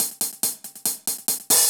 Index of /musicradar/ultimate-hihat-samples/140bpm
UHH_AcoustiHatA_140-02.wav